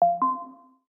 Notification Smooth 2.wav